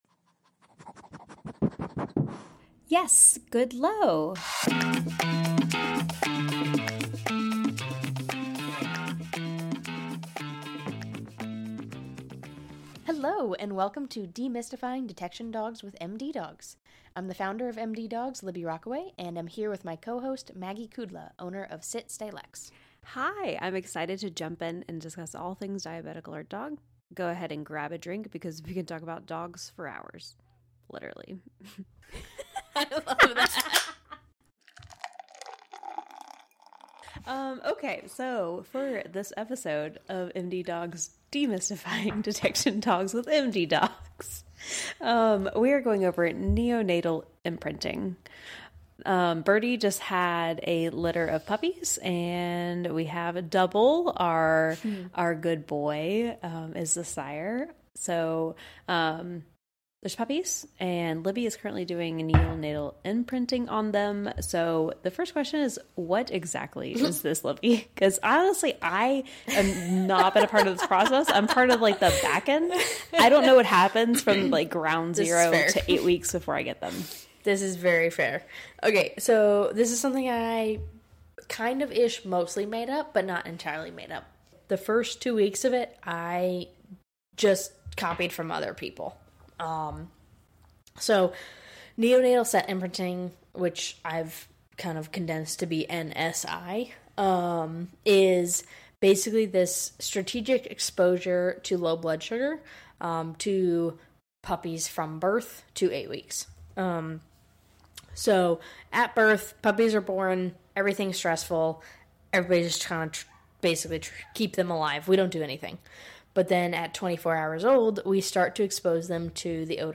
Grab a drink, and enjoy two awkward dog trainers attempt to make a podcast.